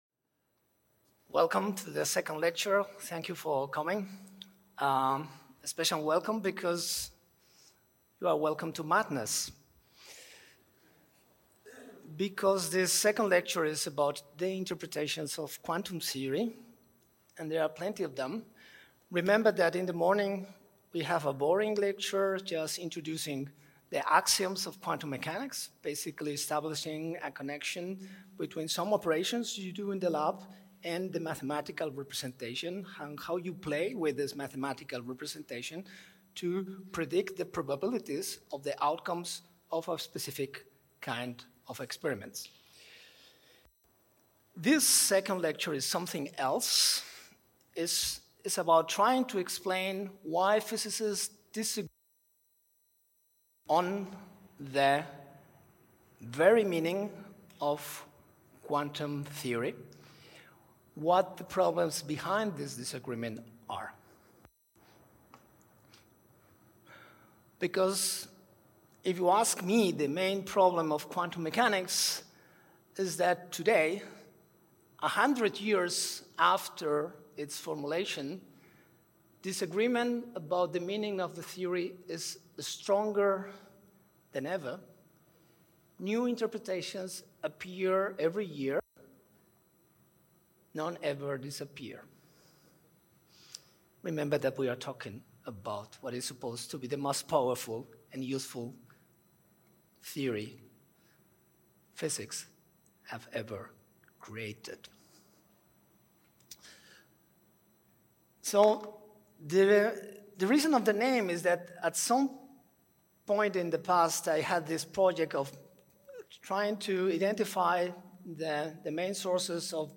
Segona conferència
celebrada a la Sala de Graus de la Facultat de Lletres de la UdG el dimarts 25 de novembre del 2025